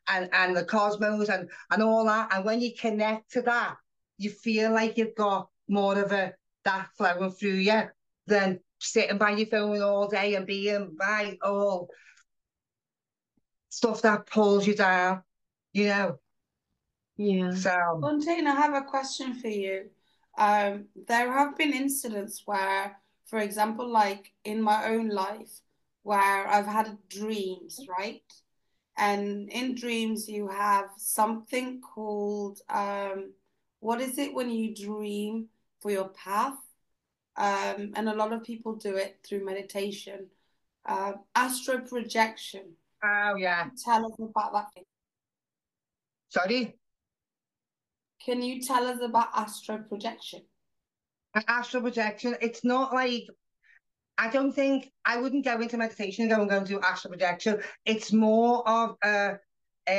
At Real Talk, Real Queens, we’re two everyday women bringing energy, laughter, and real conversations to help millennial women conquer their world with confidence, resilience, and purpose.